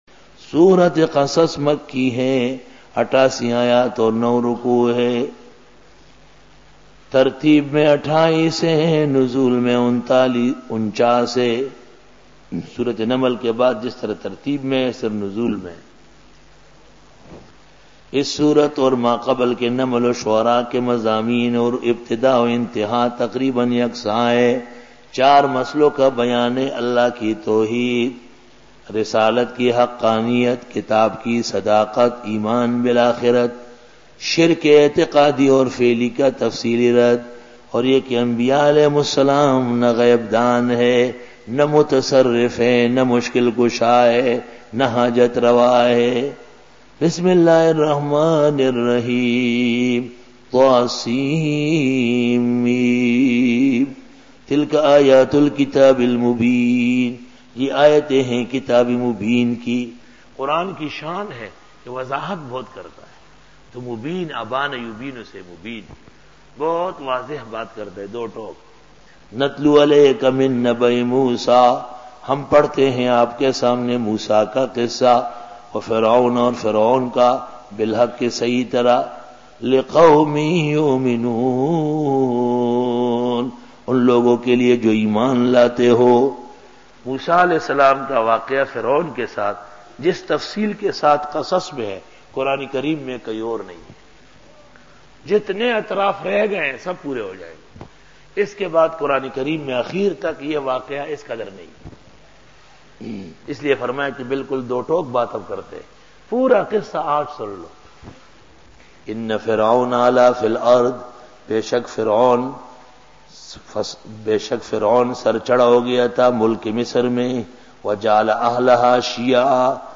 Dora-e-Tafseer 2001